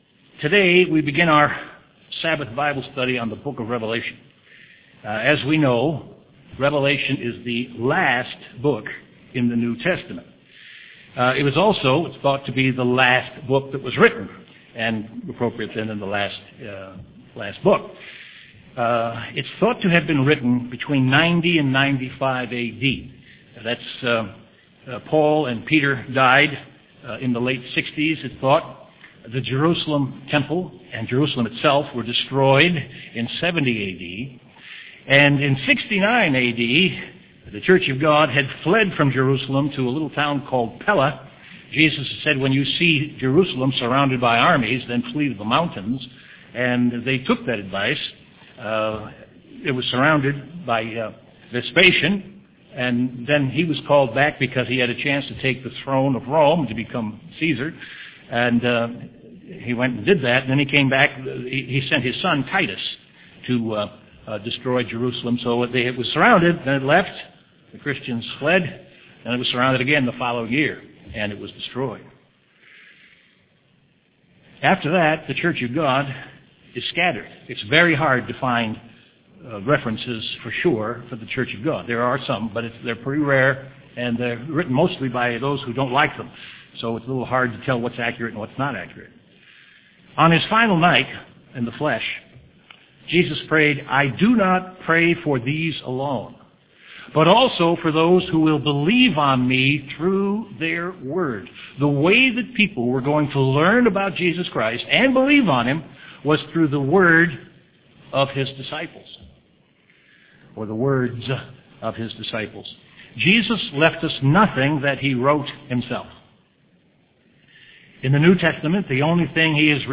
Given in Chicago, IL
UCG Sermon